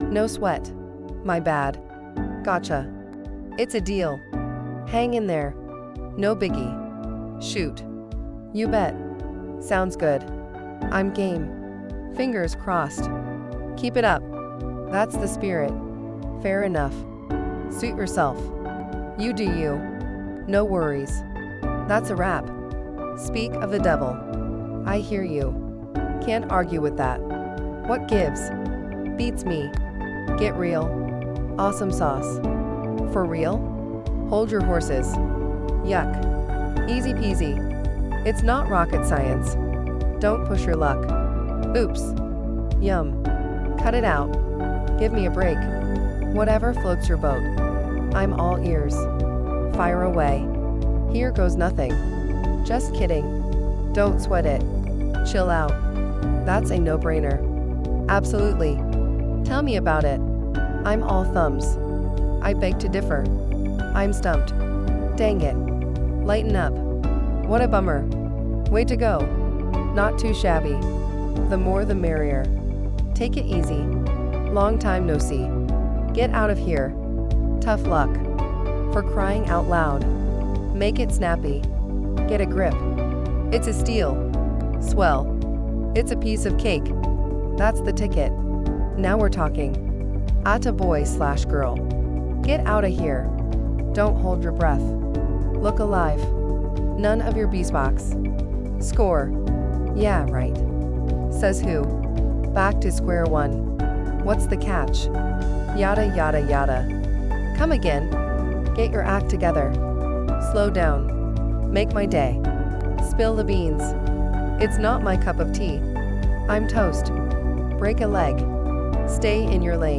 実践的なフレーズで会話力アップ、ネイティブの発音でリスニング力も向上。